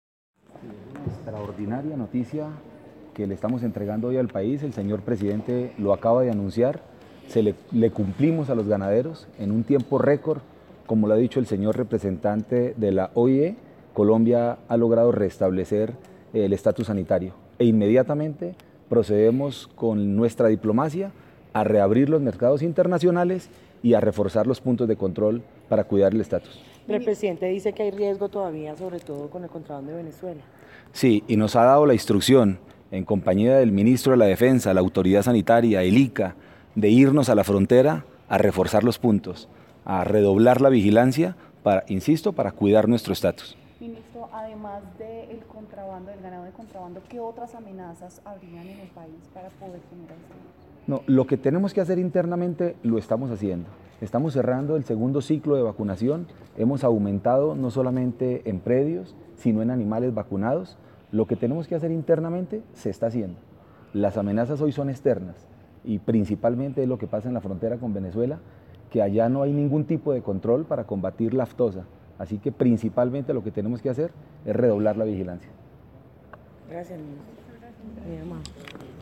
Rueda de prensa en la Presidencia de la República
Declaraciones-Ministro-de-Agricultura_2